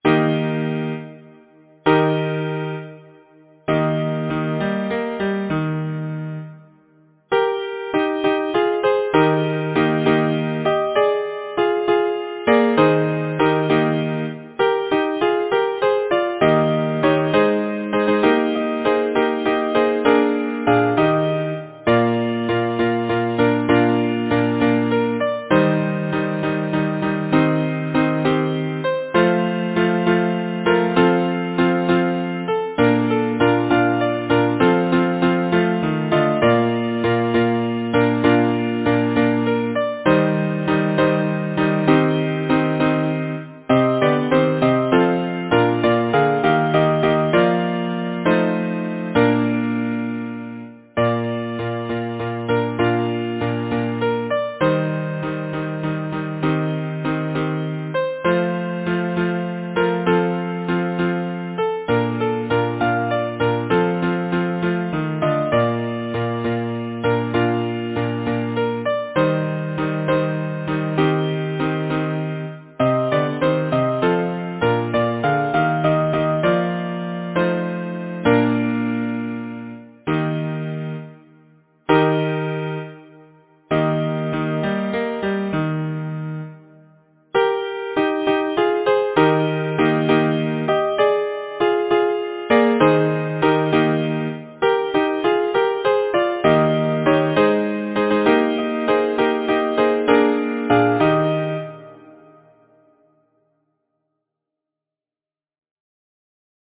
Title: Spring has come Composer: Frank William Westhoff Lyricist: Bettie Martincreate page Number of voices: 4vv Voicing: SATB Genre: Secular, Partsong
Language: English Instruments: A cappella